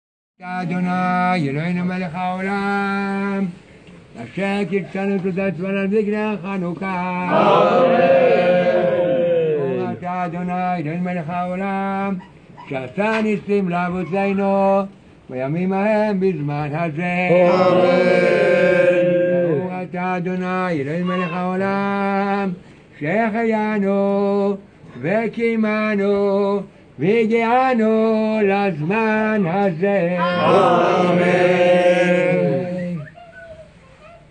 מברך על הנר הראשון של חנוכה במעמד רבים.